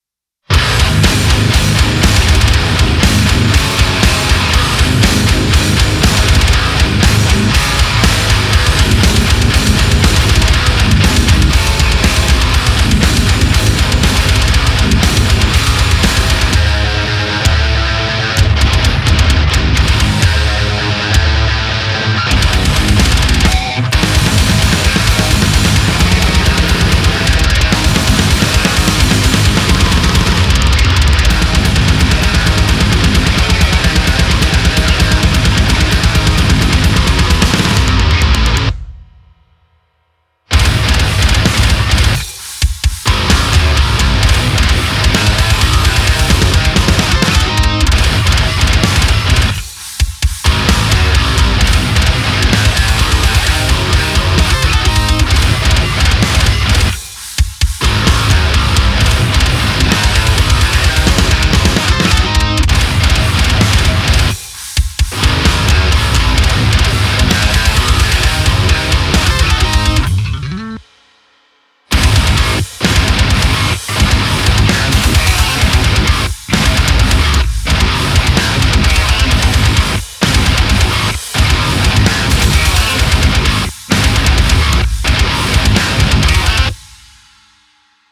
Тяжёлый рок (Тест GuitarRig и addictive drums 2)
Здравствуйте ребятки котятки, ради эксперемента решил опробовать в деле addictive drums 2(без подмесов) и GuitarRig.